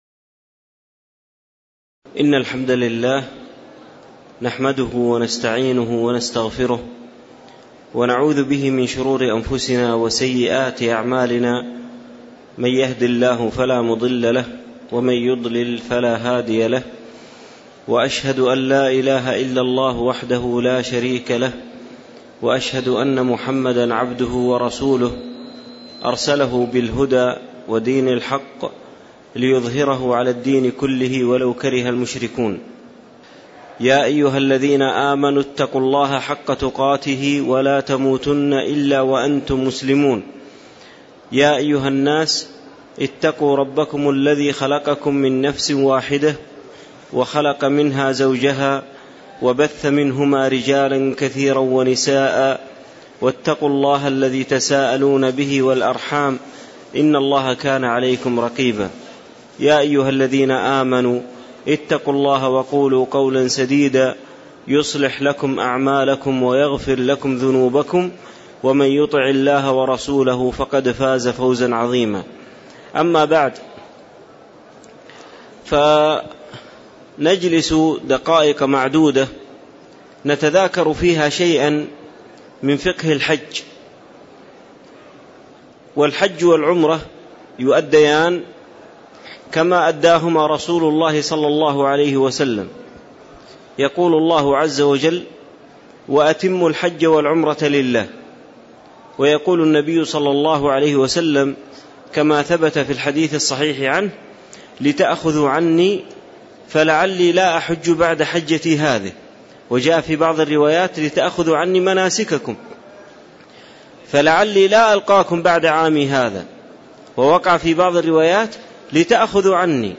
تاريخ النشر ١٧ ذو القعدة ١٤٣٧ هـ المكان: المسجد النبوي الشيخ